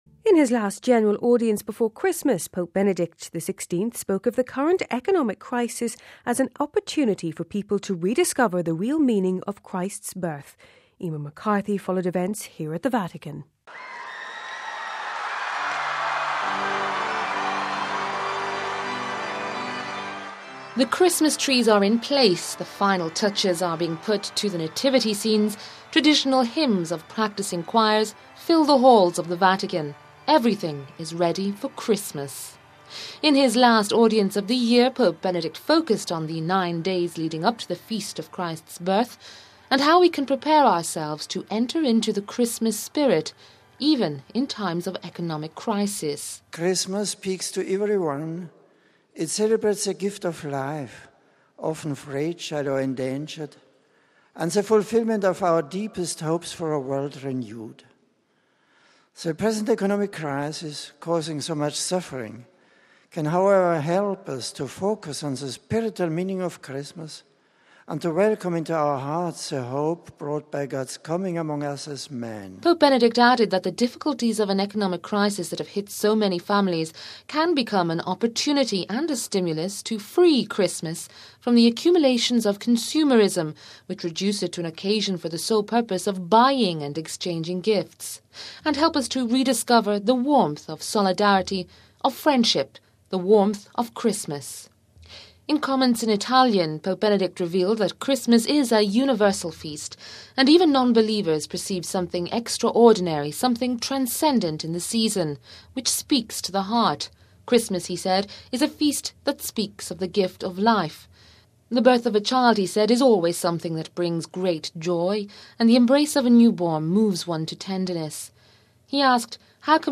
The Christmas trees are in place, the final touches are being put to the nativity scenes, traditional hymns of practising choirs fill the halls of the Vatican, everything is ready for Christmas.
Then after exchanging greetings the thousands who packed to the audience hall and to the distinctive sound of festive pipe players from the Alps, known as “zampognari,” Pope Benedict wished everyone a very happy Christmas: